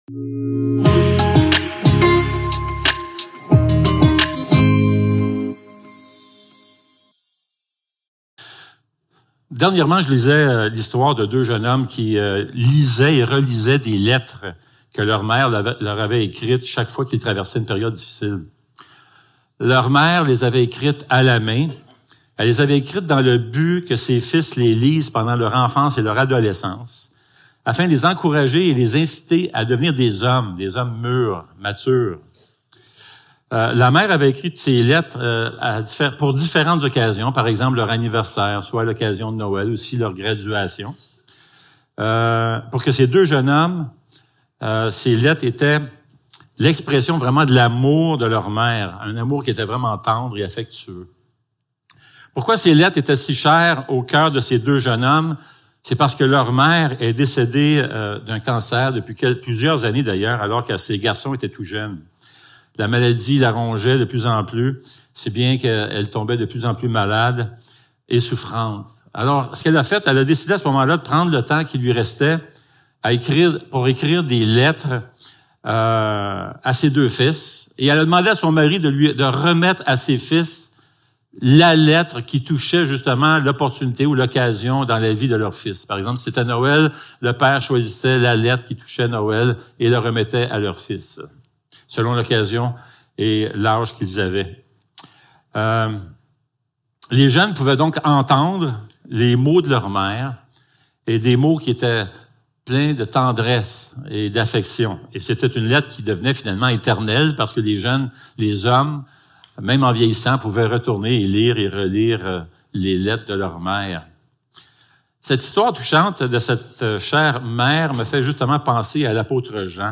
1 Jean 5.14-21 Service Type: Célébration dimanche matin Description